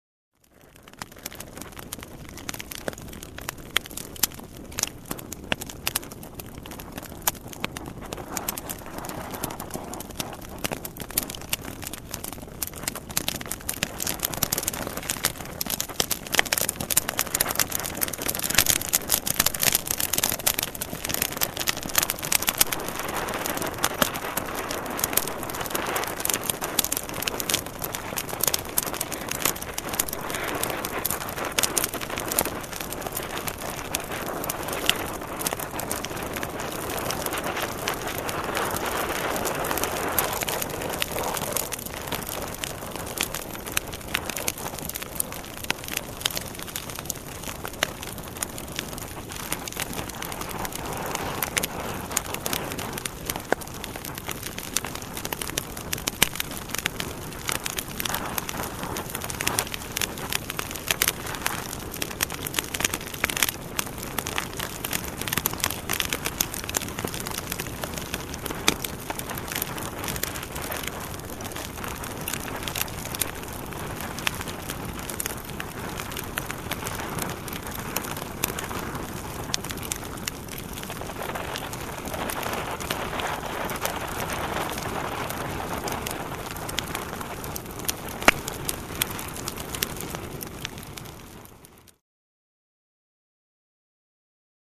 Подборка включает разные варианты: от спокойного потрескивания до яркого горения.
Звуки костра шипение мокрых дров потрескивание углей щелчки